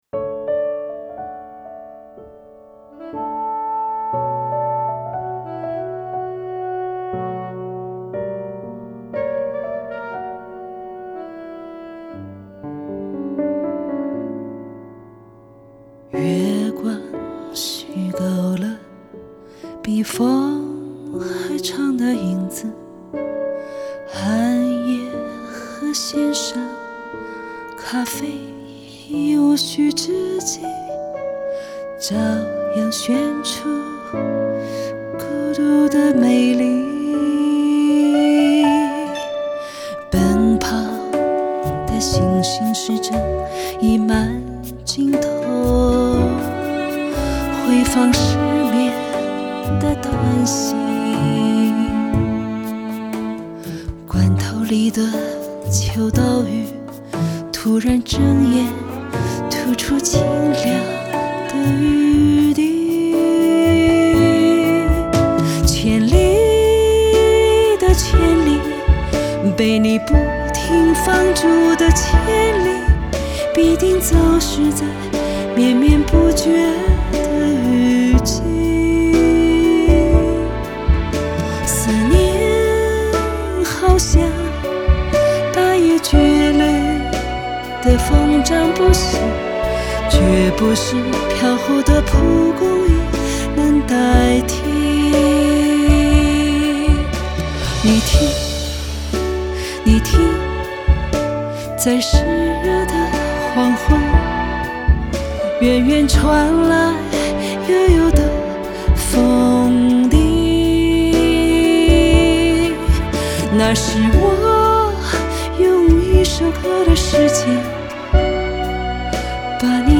女声演唱